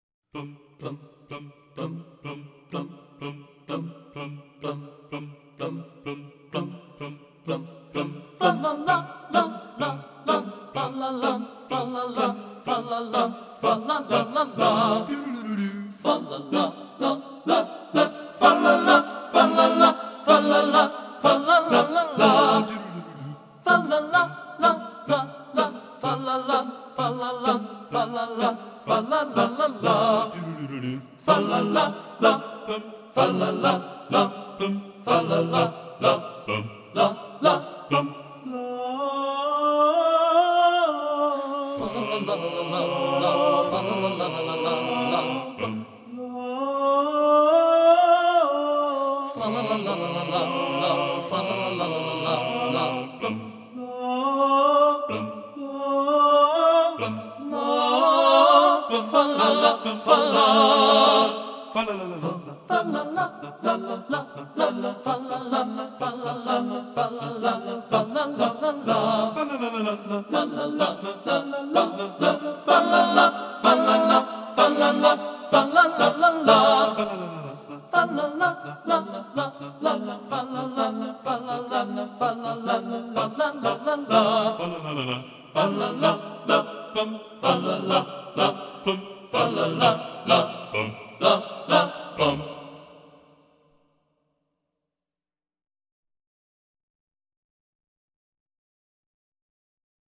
Class 1 UIL Tenor Saxophone Solos
Voicing: TTBB